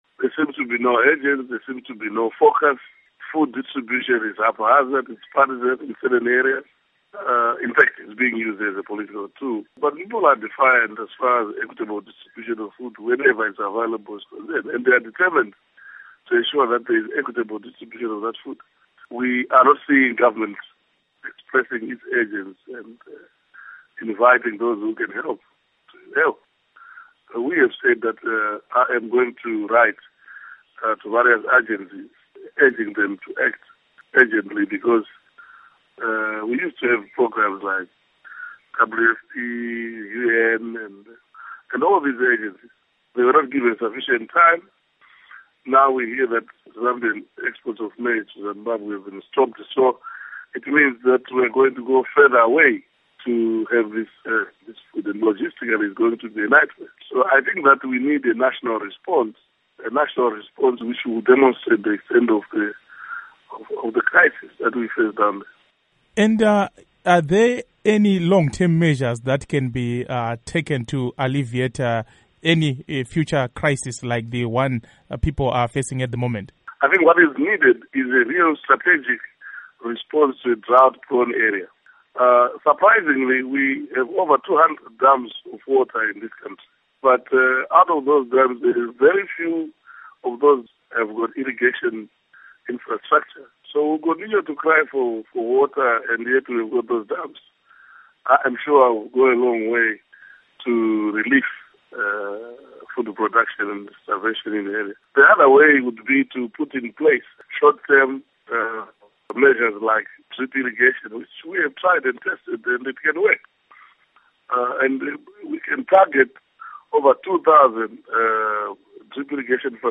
Interview With Morgan Tsvangirai